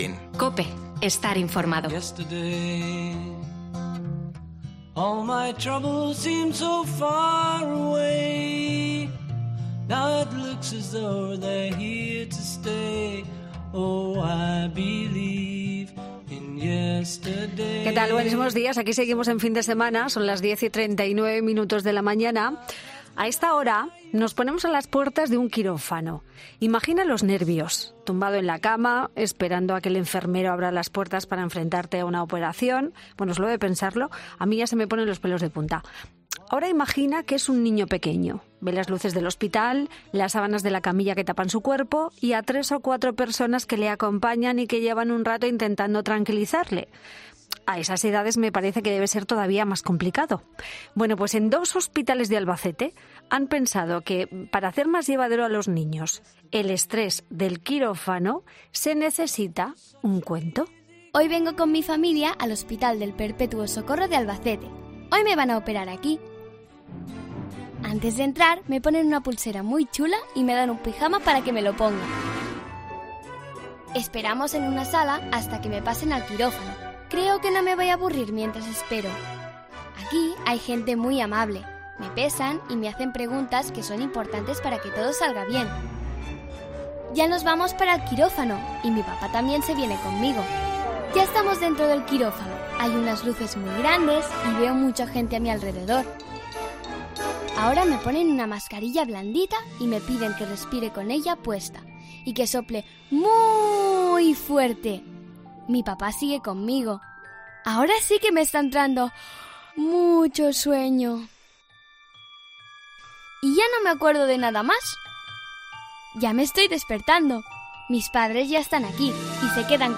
Cuentos para los más pequeños Y desde el programa de Fin de Semana en COPE hemos podido escuchar cómo es uno de esos cuentos que se le ponen a los más pequeños que se deben someter a cualquier intervención que requiera pasar por quirófano.